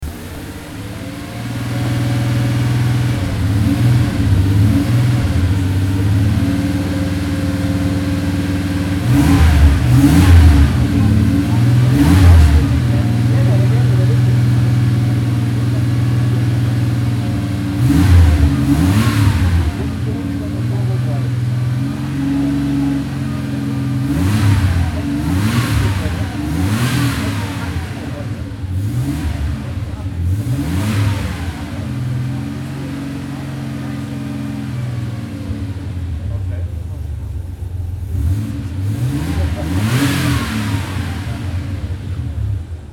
Porsche Sound Night 2017 - a loud night at the museum (Event Articles)